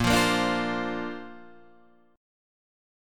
A#add9 chord {6 8 8 7 6 8} chord